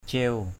/cie̞ʊ/